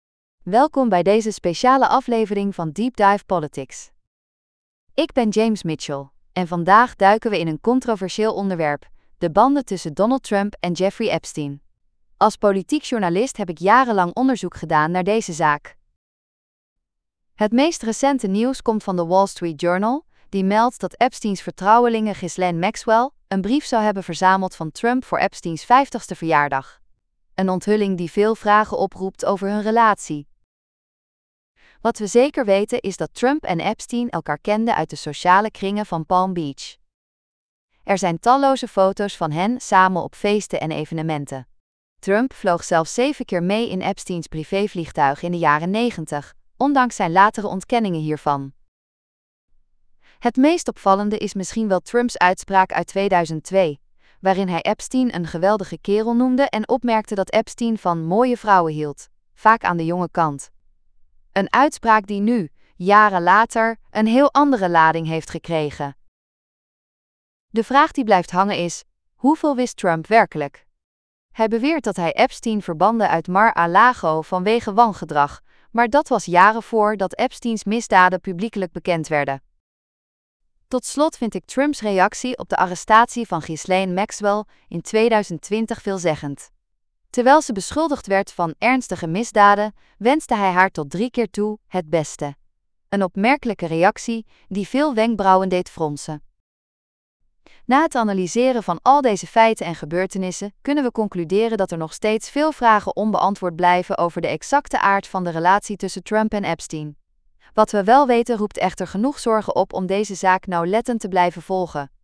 Podcast gegenereerd van tekst content (11886 karakters)